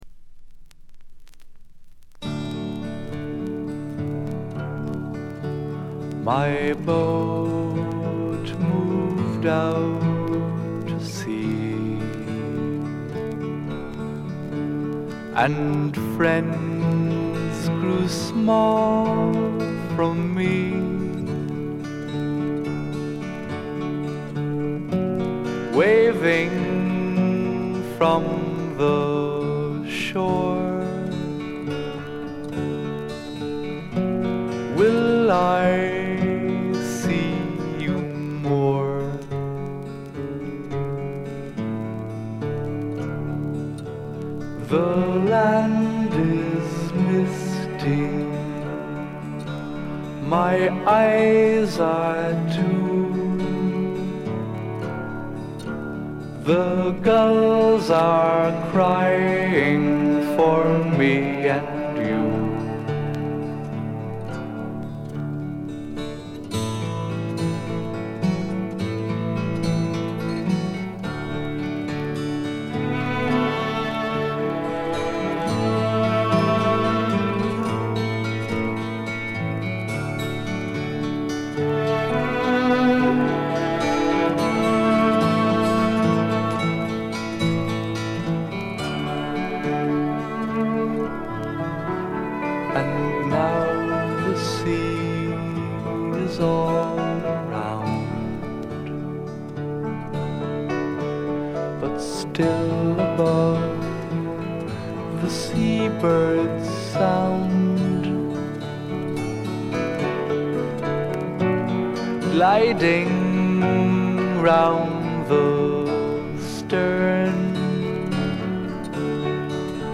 基調は霧の英国フォークでありながらも、幻想的で、ドリーミーで、浮遊感たっぷりで、アシッドな香りも・・・。
試聴曲は現品からの取り込み音源です。